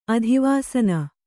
♪ adhivāsana